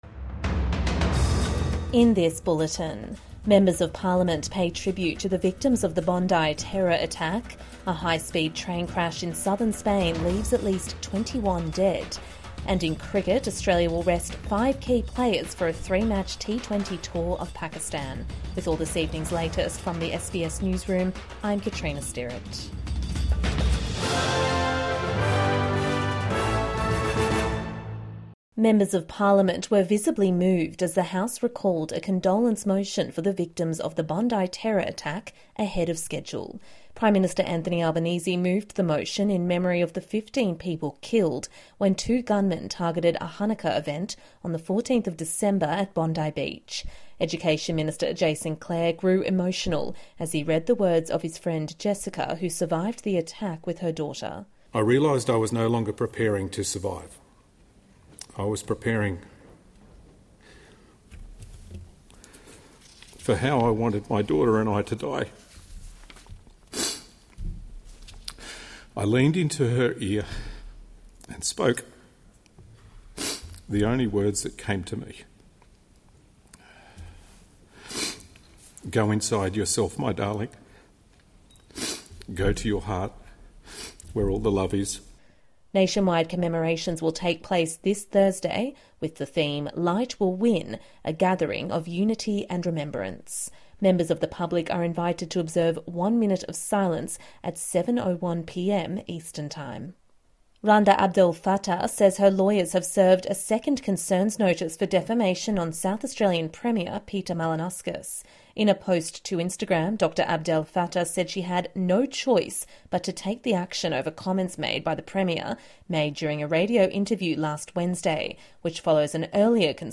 MPs pay tribute to the victims of the Bondi terror attack | Evening News Bulletin 19 January 2026